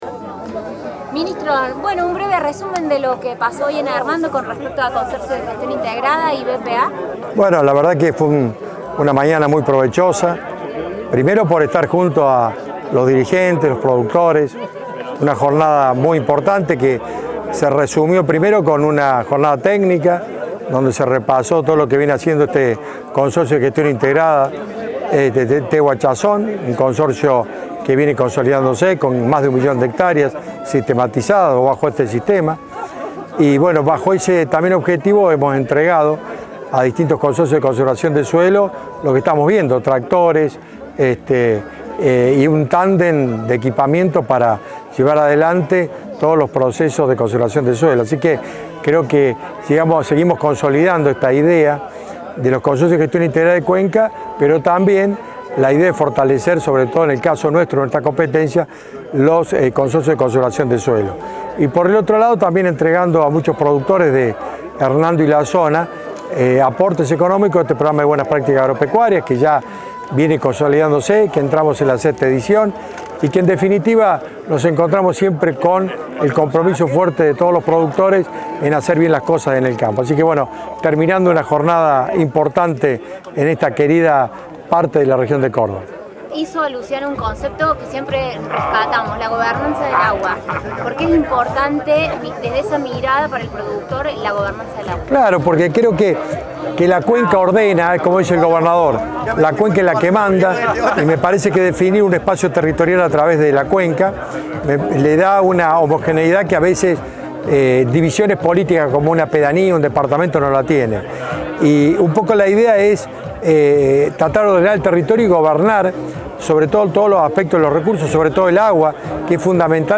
A continuación escuchamos las palabras del Ministro Sergio Busso